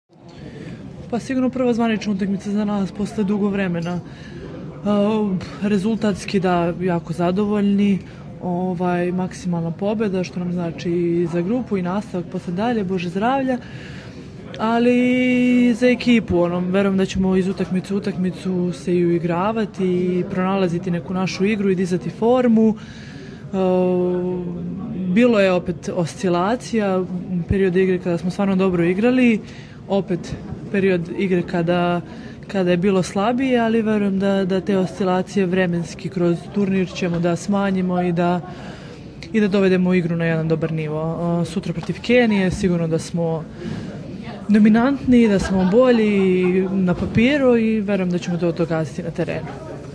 Izjava Tijane Malešević